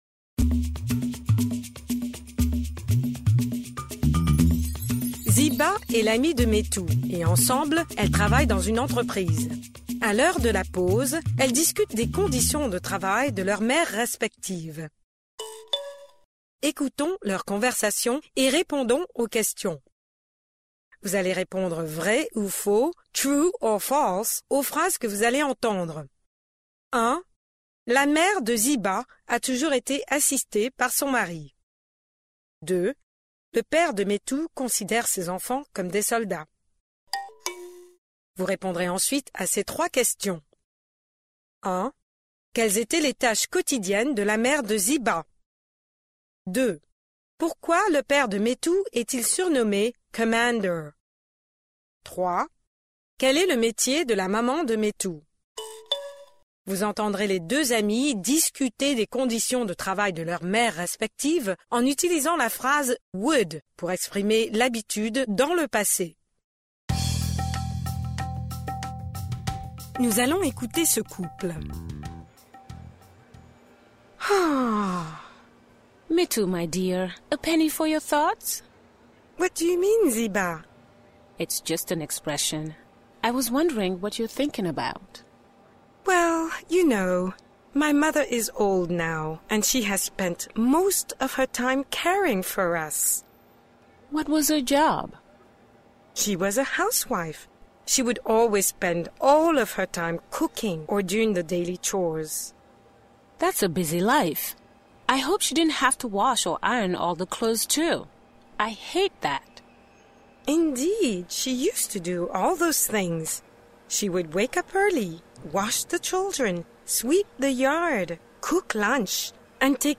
Vous entendrez les deux amis discuter des conditions de travail de leurs mères respectives, en utilisant la phrase « would », pour exprimer l’habitude.